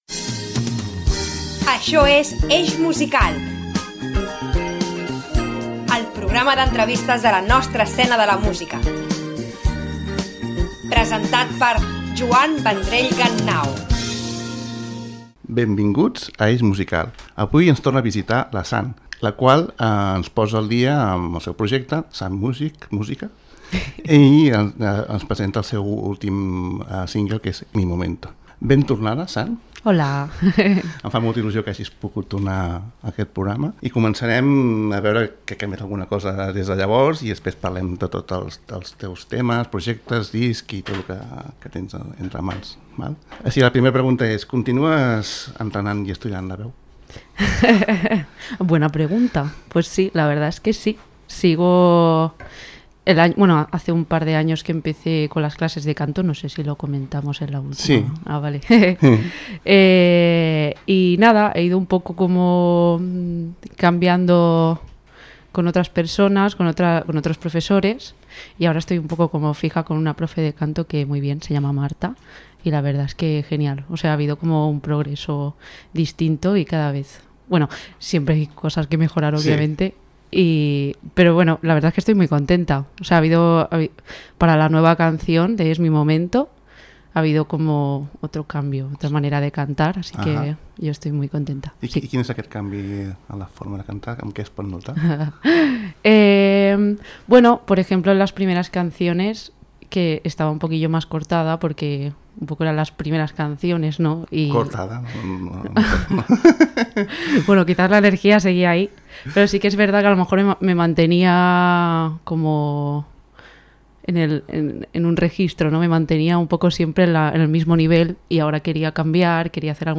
Com l’altre cop, hem tingut una agradable i divertida conversa que no té preu. https